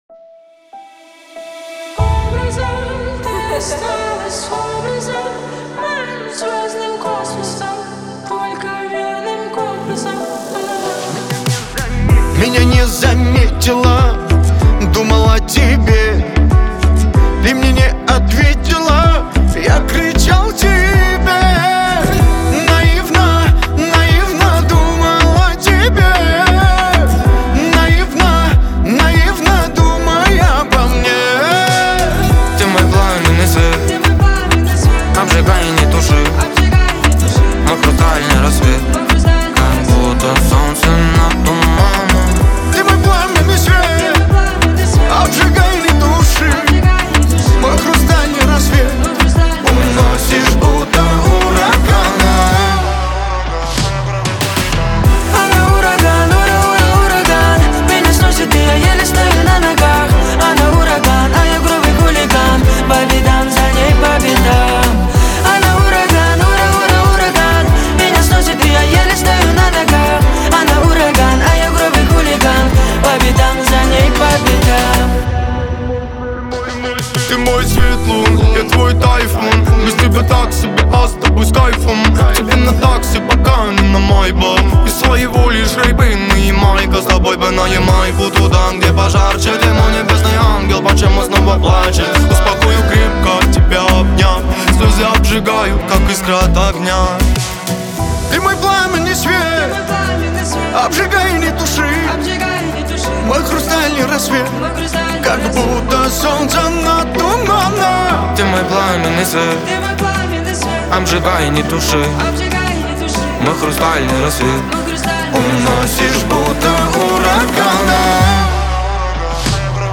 Качество: 320 kbps, stereo
Поп музыка, Кавказская музыка